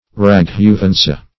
Search Result for " raghuvansa" : The Collaborative International Dictionary of English v.0.48: Raghuvansa \Ragh`u*van"sa\ (r[u^]g`[.u]*v[u^]n"s[.a]), n. [Skr.